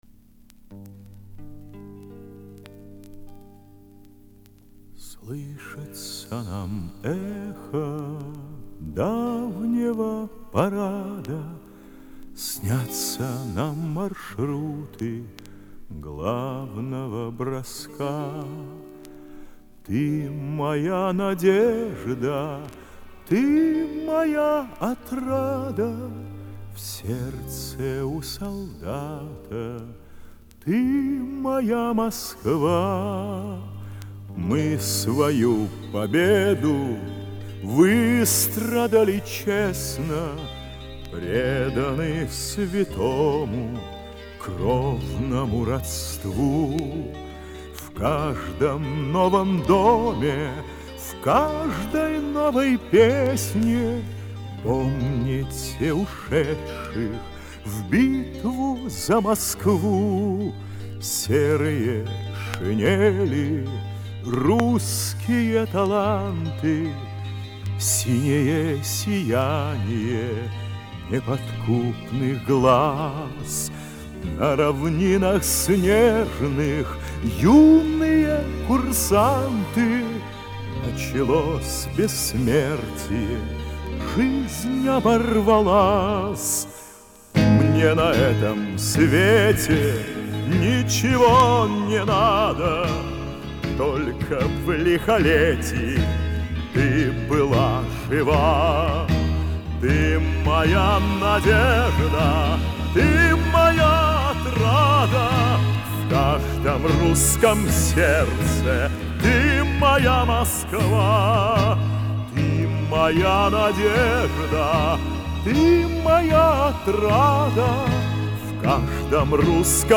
• Качество: Хорошее
• Жанр: Детские песни
патриотическая